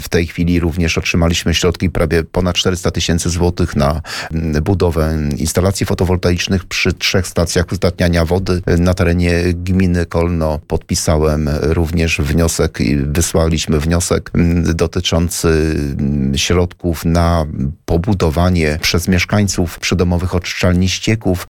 Samorządowiec mówił również o inwestycjach w odnawialną energię.